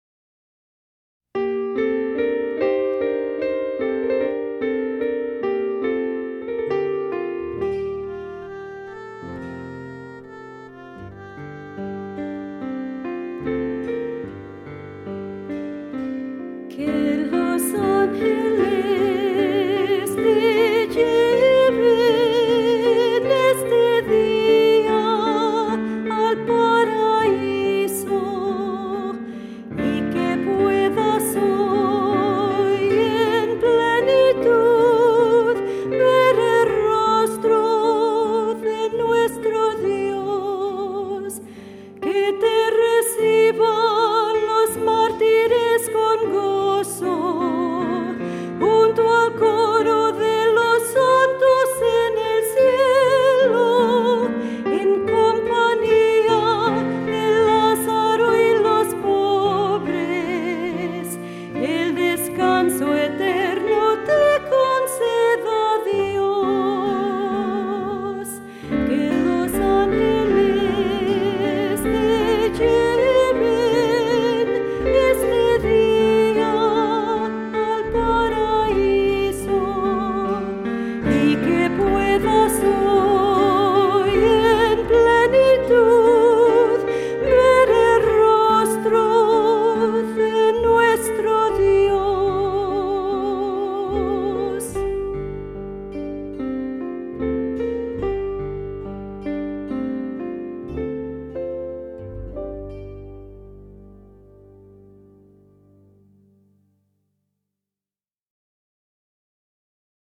Voicing: Two-part equal; Cantor; Assembly